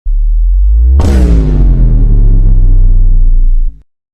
Crazy Vine Boom Efecto de Sonido Descargar
Crazy Vine Boom Botón de Sonido